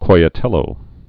(koiə-tĭlō, -tēyō, kīə-)